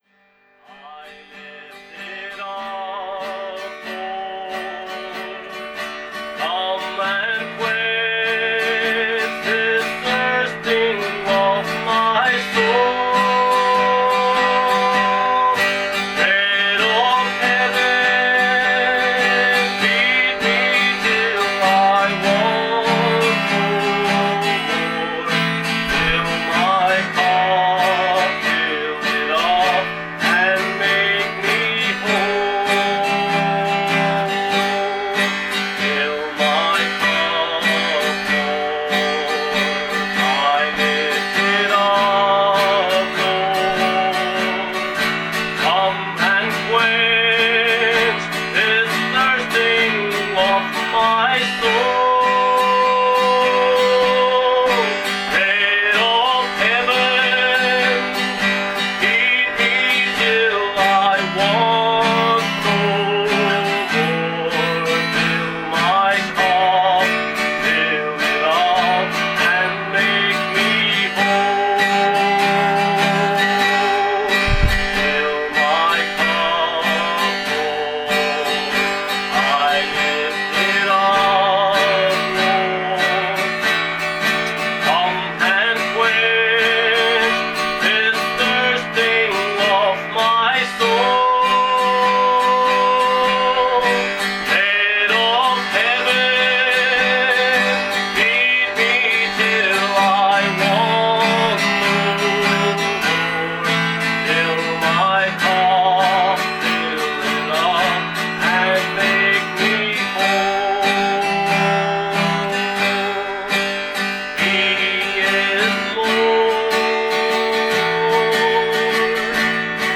Omega Ministry - Audio Sermons